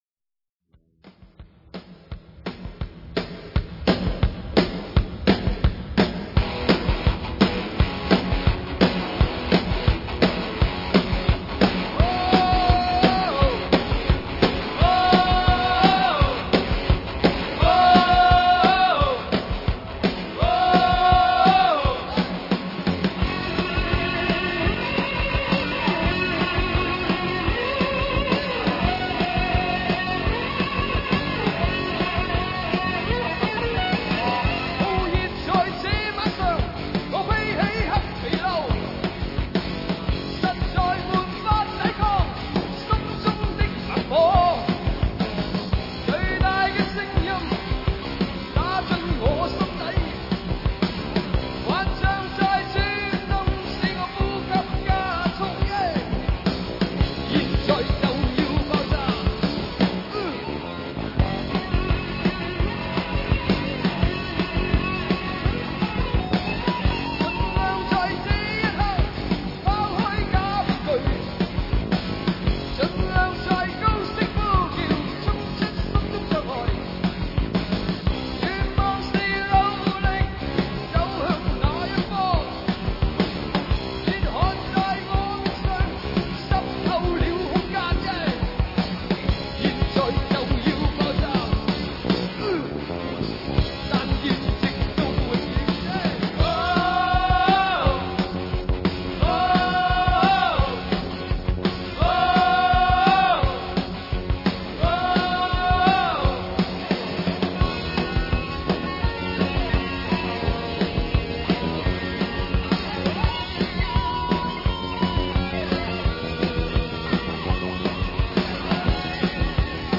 1988年【北京演唱会】
主唱